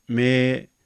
Mē - long vowel sound | 502_14,400